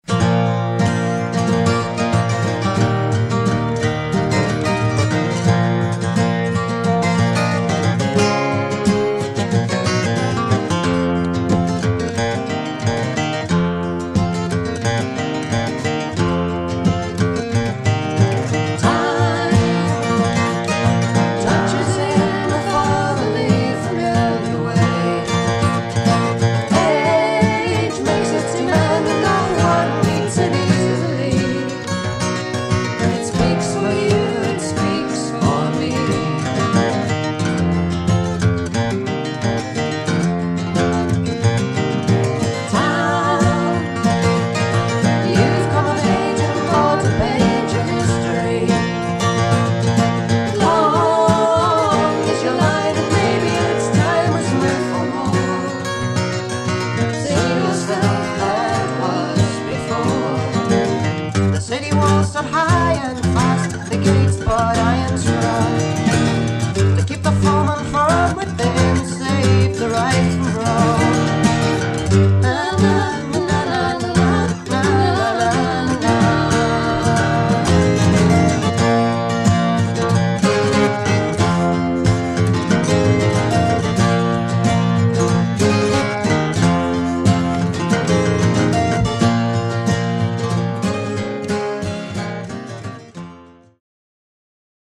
Recorded 1971 in San Francisco and Cambria, USA
remastered from the original tapes